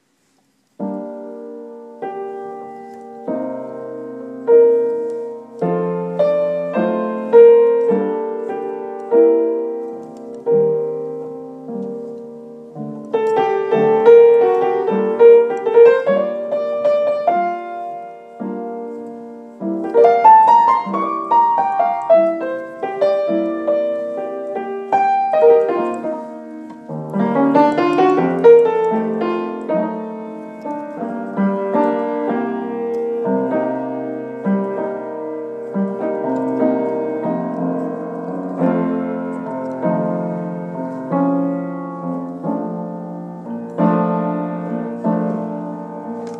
Music
piano, my style is always a bit jazzy.